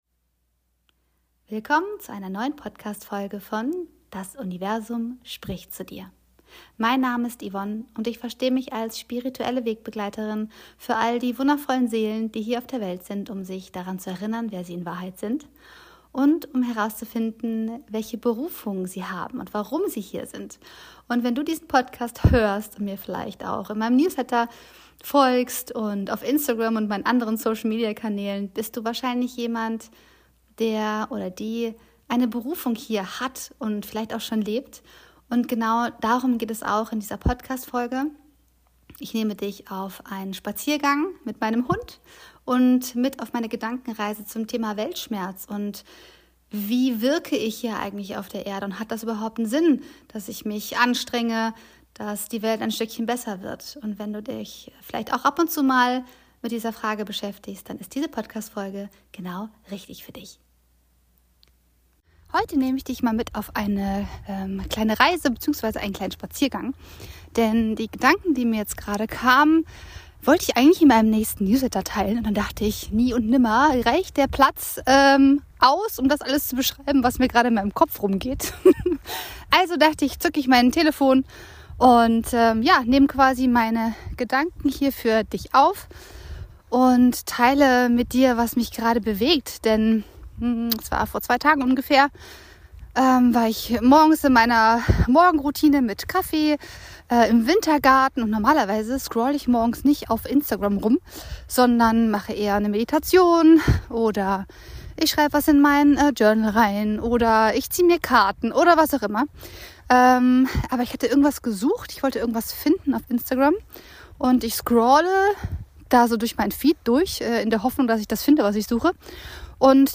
Ich nehme dich in dieser Folge mit auf einen Spaziergang, erzähle dir von meiner letzten Erfahrung mit Weltschmerz und möchte dich daran erinnern, wie wichtig es für die Welt ist, dass du deinen Platz in dieser Welt einnimmst und einen Unterschied machst.